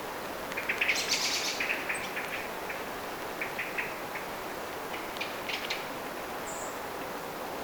keltanokkarastaslintu ja laulurastas
keltanokkarastaslintu_ja_laulurastas.mp3